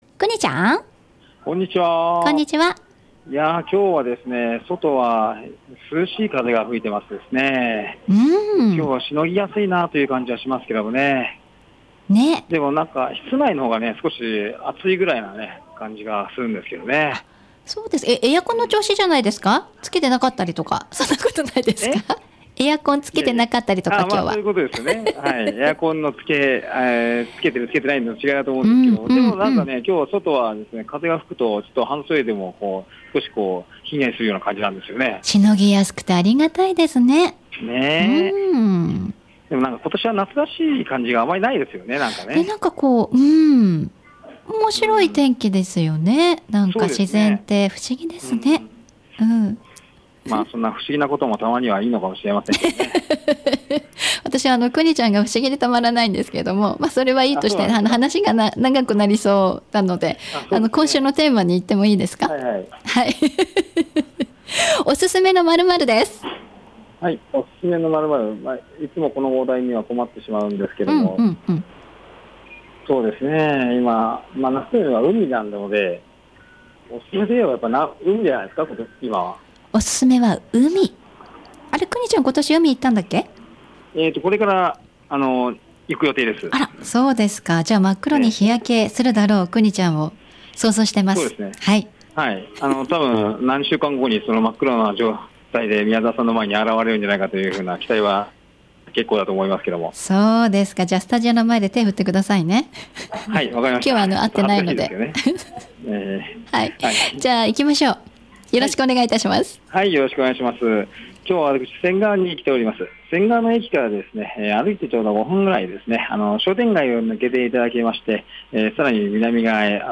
さて今週は仙川に出没です。